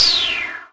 spell_b.wav